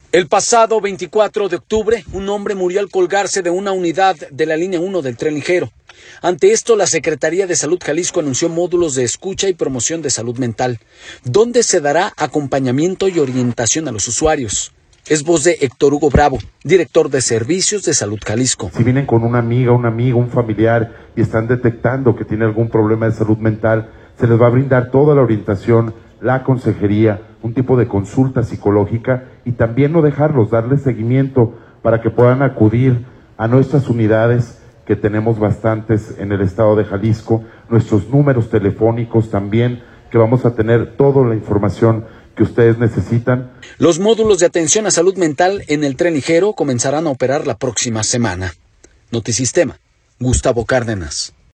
audio El pasado 24 de octubre un hombre murió al colgarse de una unidad de la Línea Uno del Tren Ligero, ante esto la Secretaría de Salud Jalisco anunció módulos de escucha y promoción de salud mental, donde se dará acompañamiento y orientación a los usuarios, es voz de Héctor Hugo Bravo, director de Servicios de Salud Jalisco.